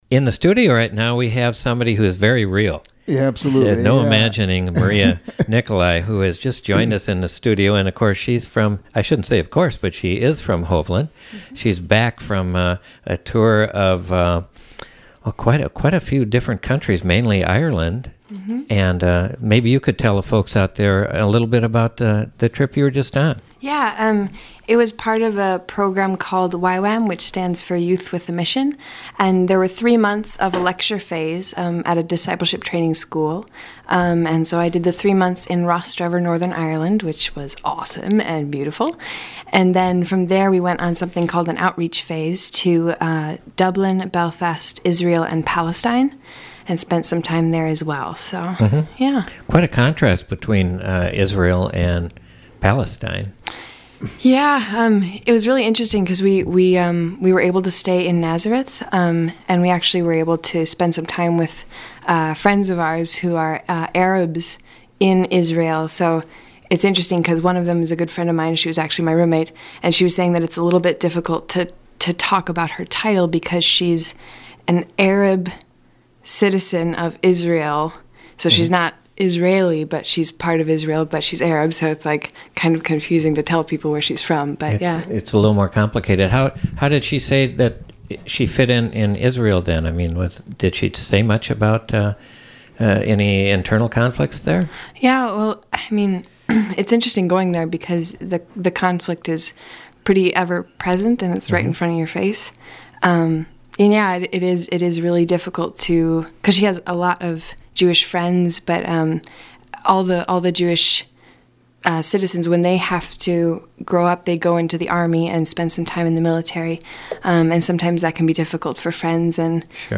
Learn about what she did, and hear some gorgeous vocals. Program: Live Music Archive The Roadhouse